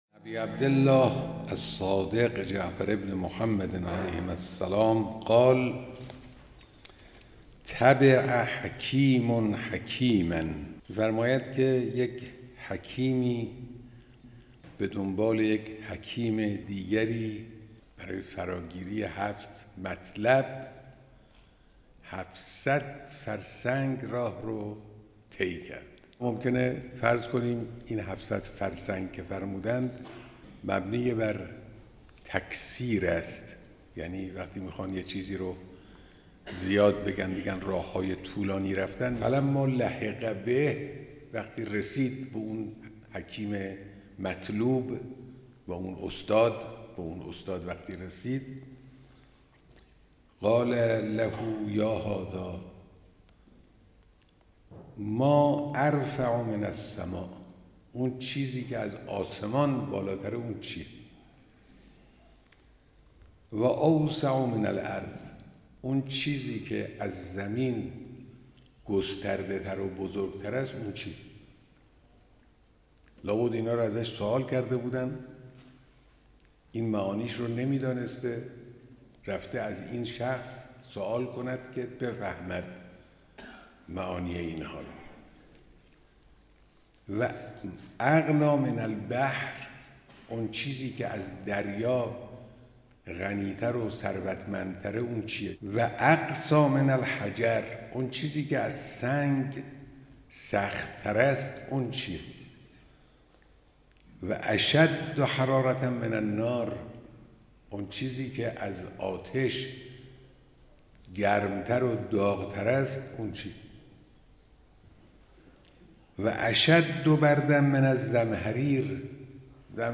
شرح حدیث اخلاقی مقام معظم رهبری / 7 نکته مهم در زندگی